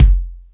Lotsa Kicks(41).wav